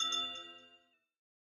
resonate2.ogg